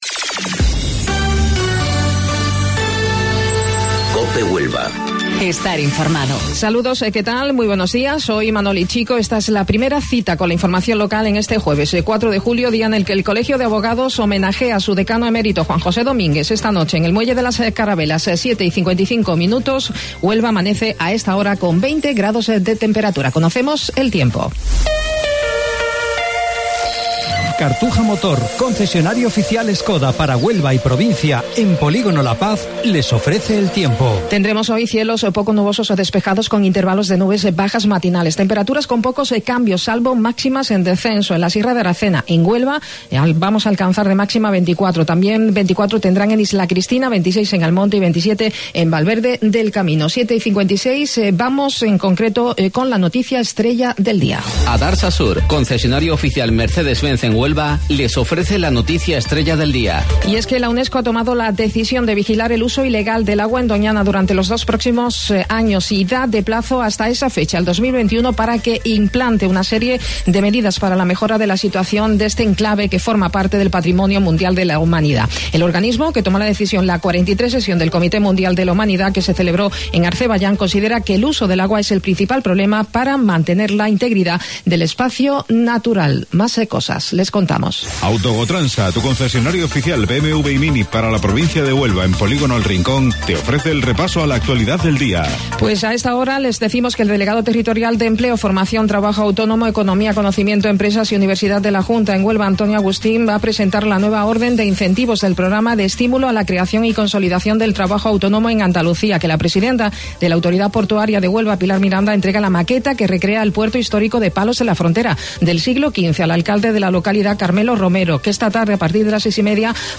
AUDIO: Informativo Local 07:55 del 4 de Julio